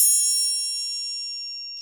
Index of /90_sSampleCDs/Roland L-CD701/PRC_Latin 2/PRC_Triangles
PRC TRIANG08.wav